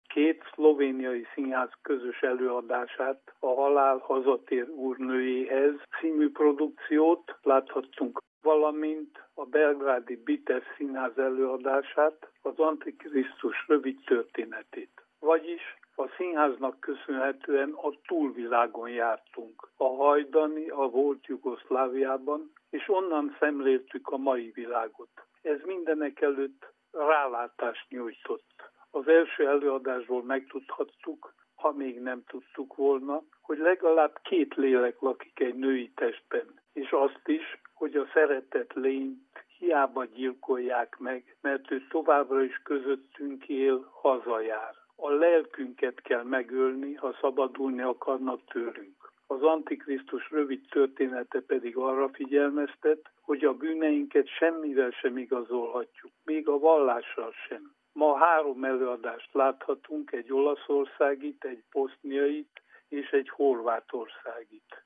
tudósítása